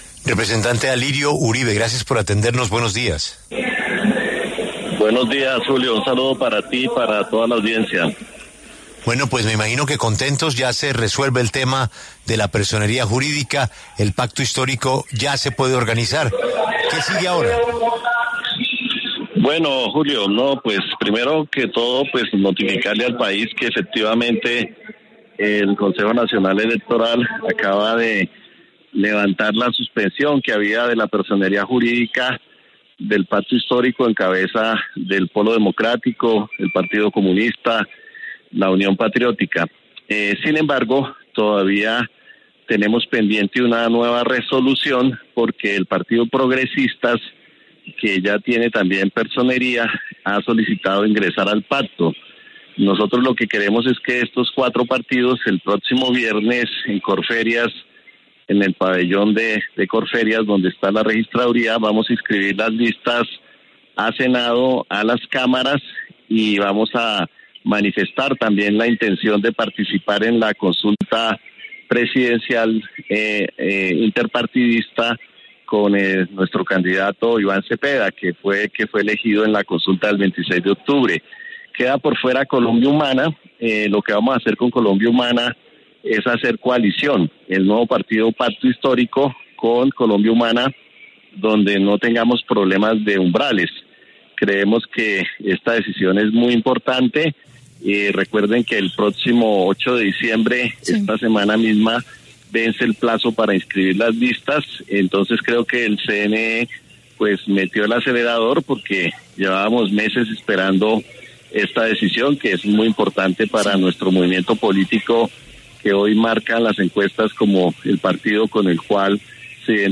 El representante Alirio Uribe pasó por los micrófonos de La W, y habló sobre el reconocimiento de la personería jurídica del Pacto Histórico.